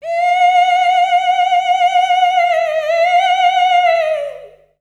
Index of /90_sSampleCDs/Voices_Of_Africa/SinglePhrasesFemale
19_Ee_UndulatingLow.WAV